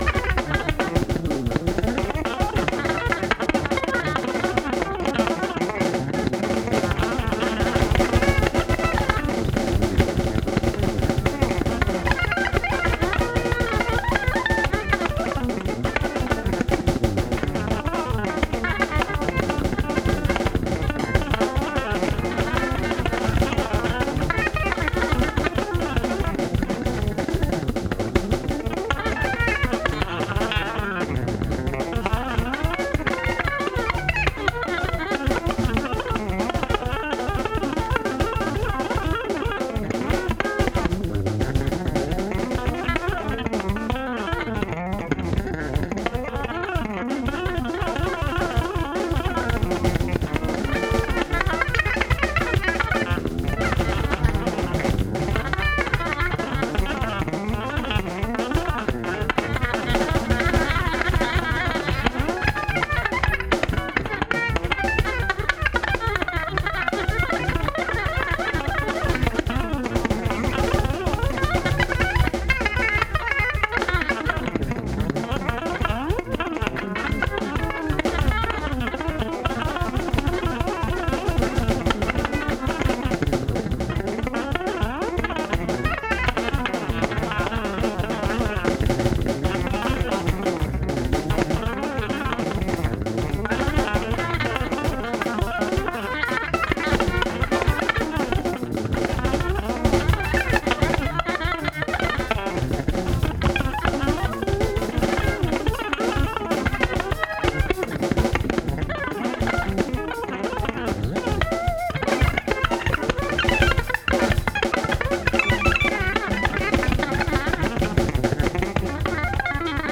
ですから、”五月のジャム・セッション”という意味になります。
緊張感ある合奏でありながらも、のびのびと楽しそうな演奏風景が目に浮かびます。